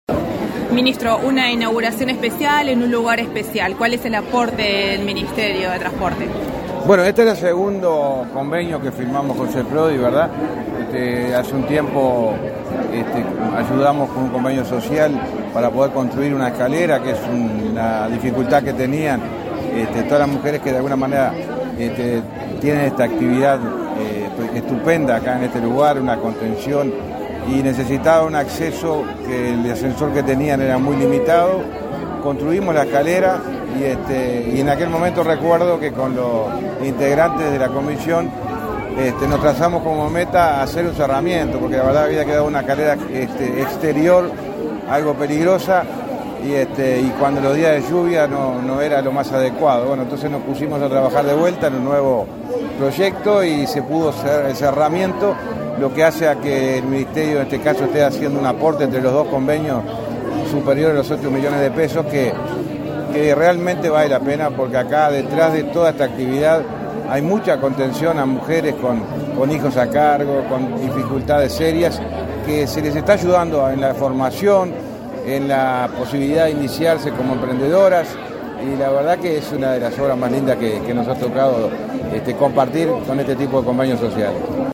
Entrevista al ministro del MTOP, José Luis Falero
Tras el evento, el ministro José Luis Falero realizó declaraciones a Comunicación Presidencial.